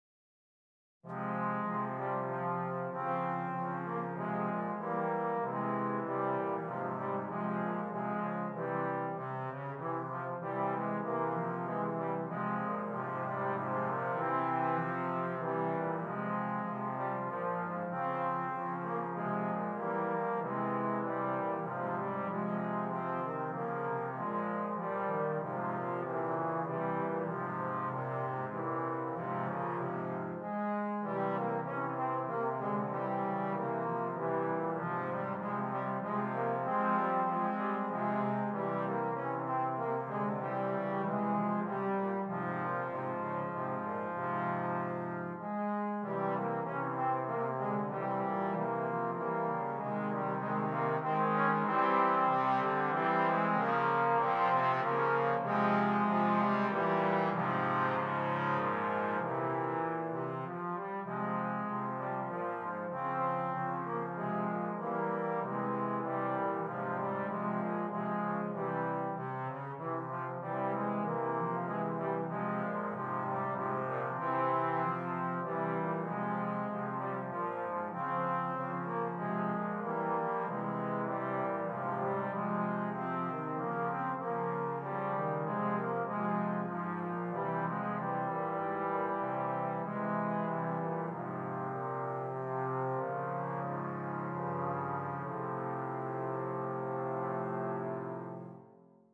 Brass Band
3 Trombones